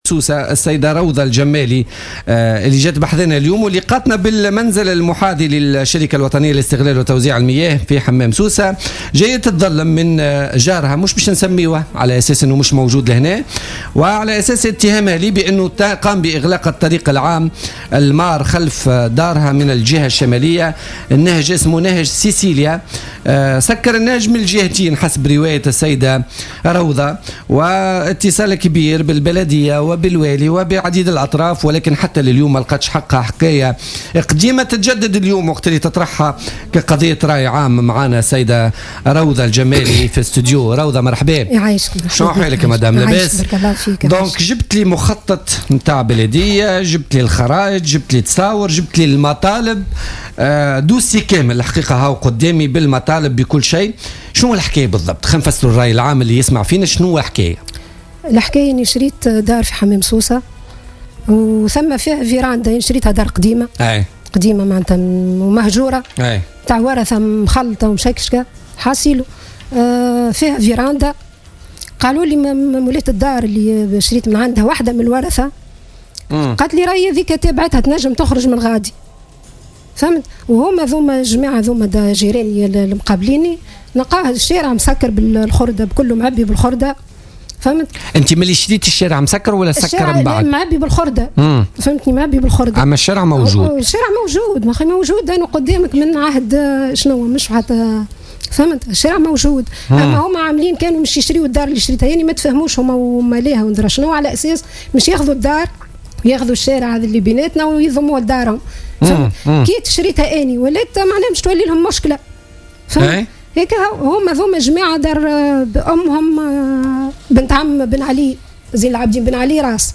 طلبت مواطنة تقطن بحمام سوسة اليوم الخميس 2 جوان 2016 من خلال حضورها في برنامج بوليتيكا مساعدتها على ايجاد حل لإشكال عقاري يتمثل في اقدام جارها على إغلاق طريق عام يمر خلف منزلها والإستحواذ عليه وضمه إلى مساحة منزله.